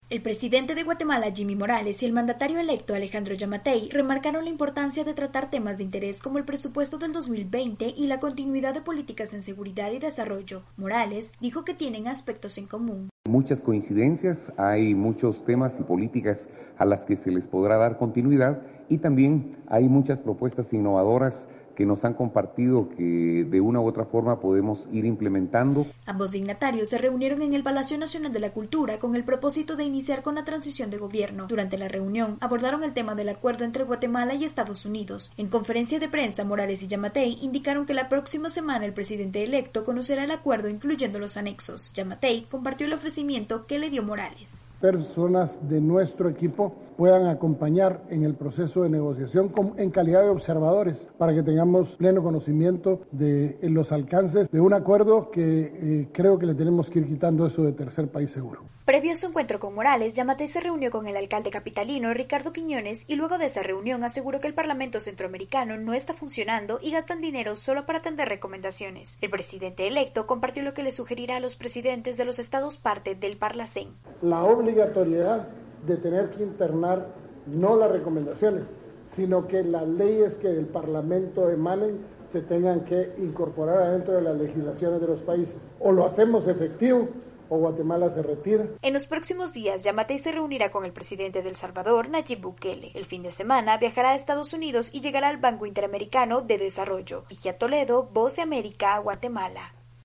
VOA: Informe desde Guatemala